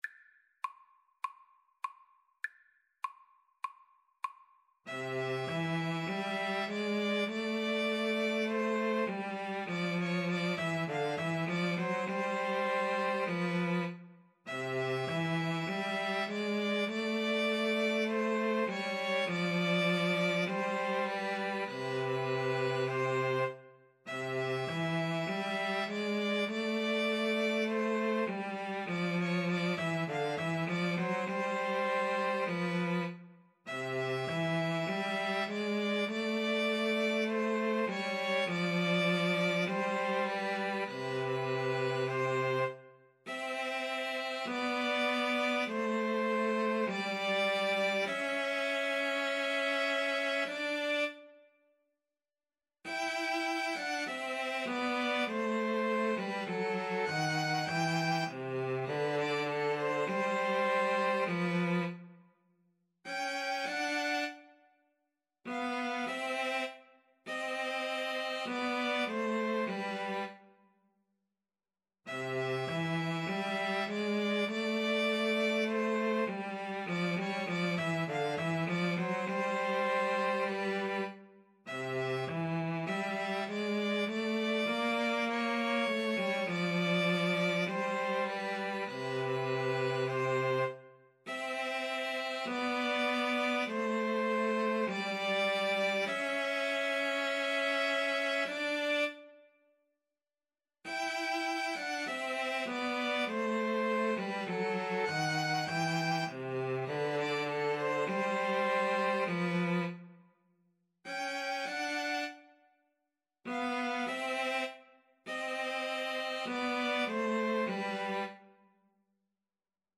4/4 (View more 4/4 Music)
Classical (View more Classical flute-violin-cello Music)